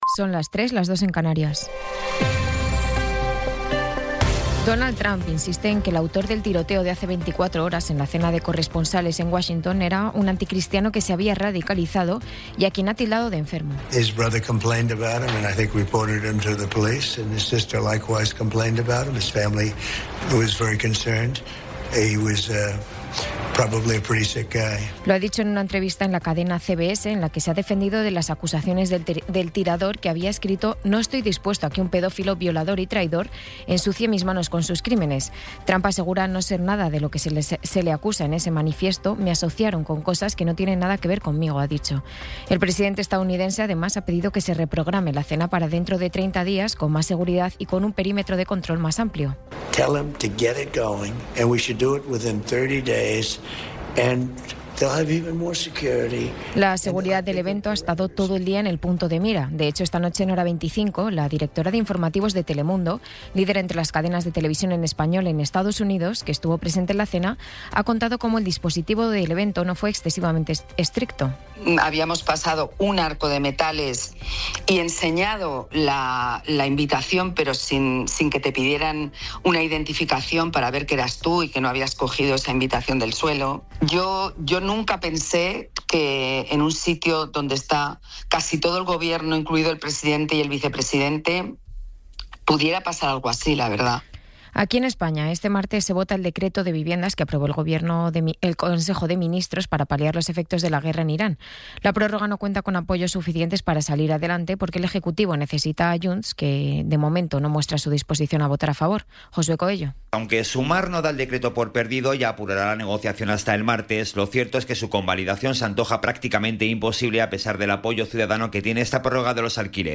Resumen informativo con las noticias más destacadas del 27 de abril de 2026 a las tres de la mañana.